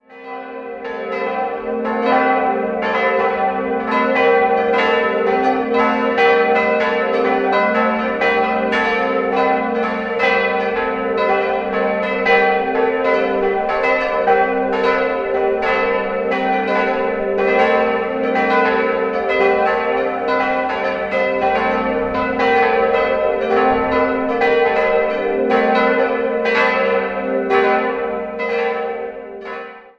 Architekt war Johann Baptist Schott aus München. 4-stimmiges Geläut: g'-a'-c''-d'' Die Glocken wurden 1946 von Karl Hamm in Regensburg gegossen.